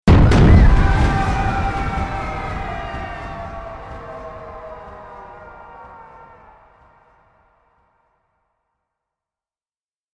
دانلود آهنگ نبرد 3 از افکت صوتی انسان و موجودات زنده
جلوه های صوتی